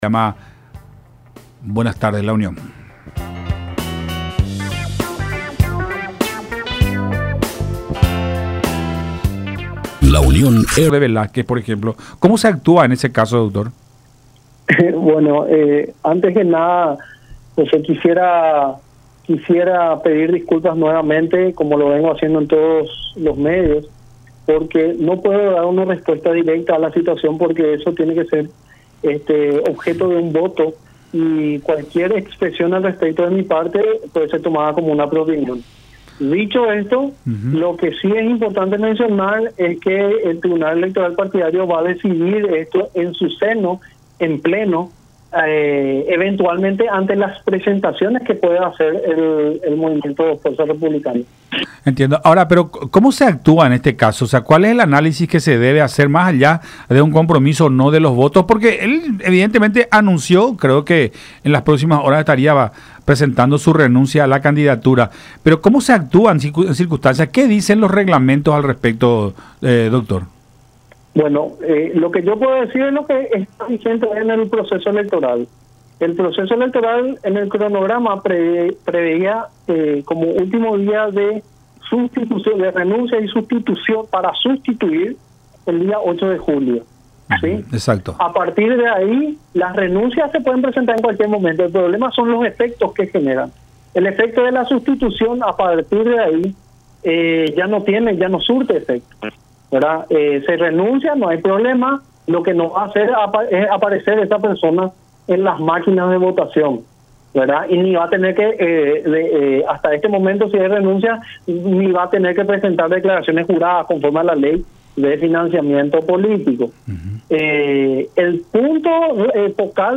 diálogo con Buenas Tardes La Unión por Unión TV y radio La Unión.